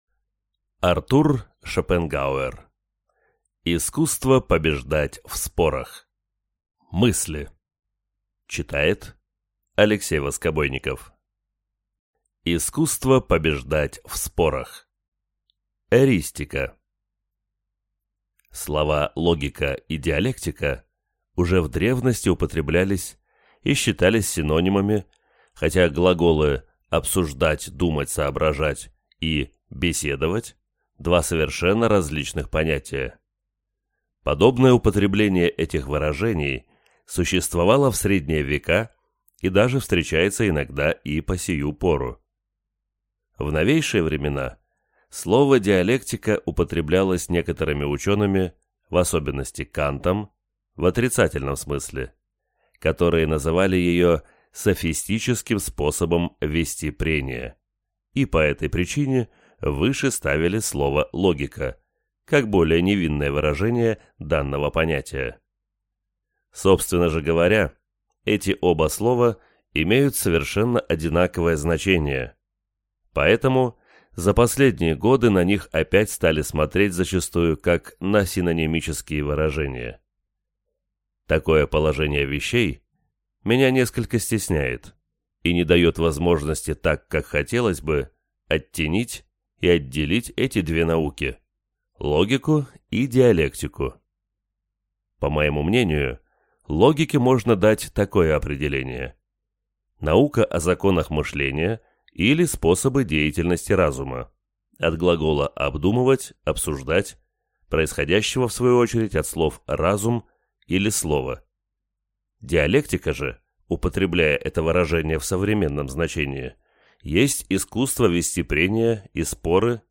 Аудиокнига Искусство побеждать в спорах. Мысли | Библиотека аудиокниг